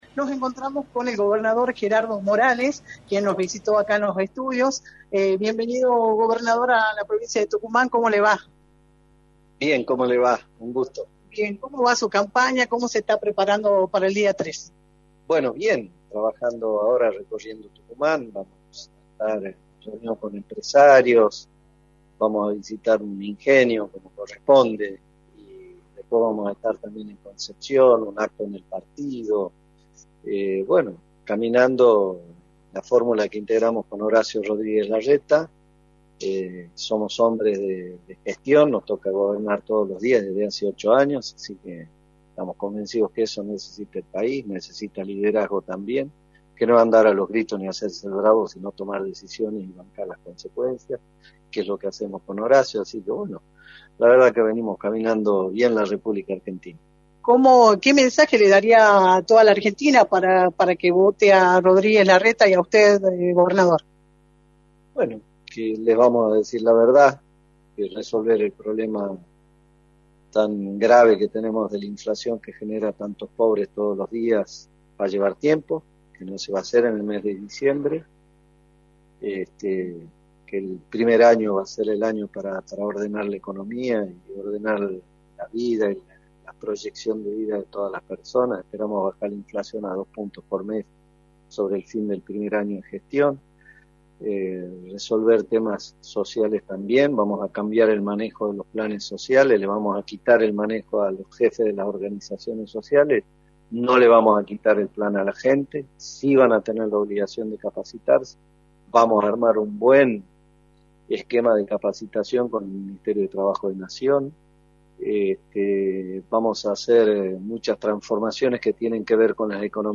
“Somos hombres de gestión, nos toca gobernar todos los días desde hace 8 años, eso necesita el país, necesita liderazgo que no es andar a los gritos ni hacerse el bravo, sino tomar decisiones y acatar las consecuencias” señaló Gerardo Morales en entrevista para Radio del Plata Tucumán, por la 93.9.